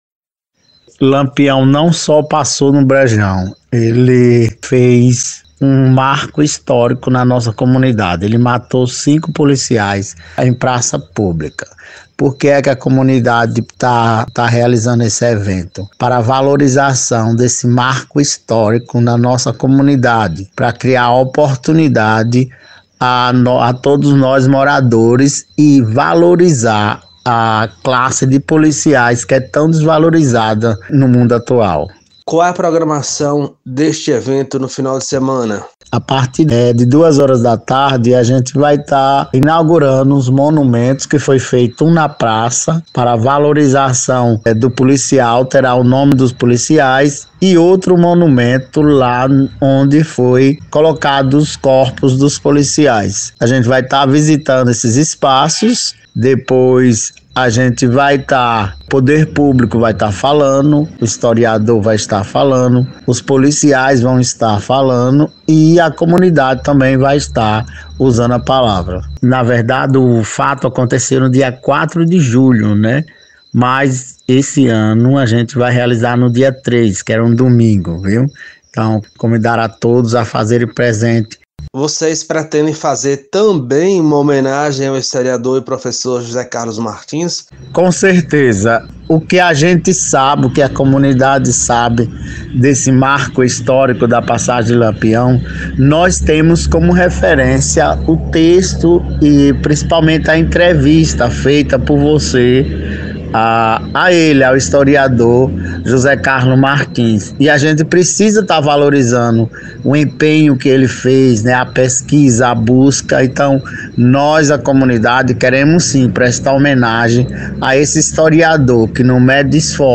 Reportagem: passagem do Lampião no Brejão da Caatinga